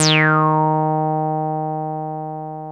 303 D#3 4.wav